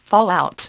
1 channel